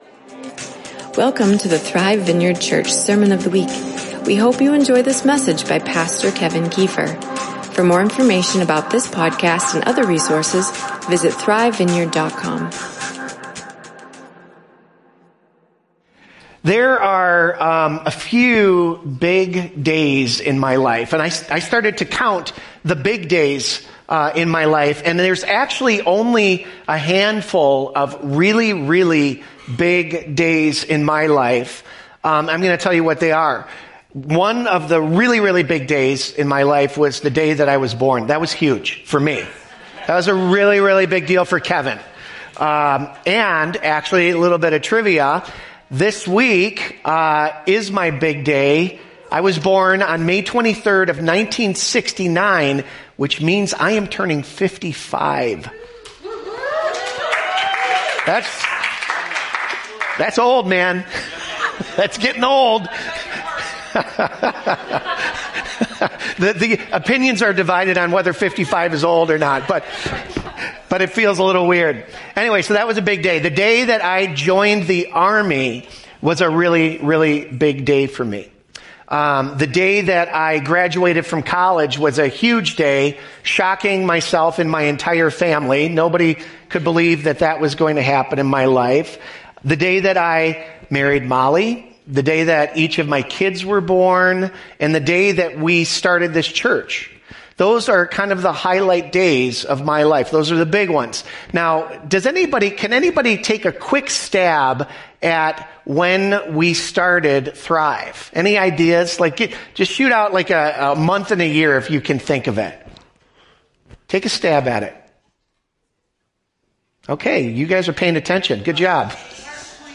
Be Filled: A Pentecost Sermon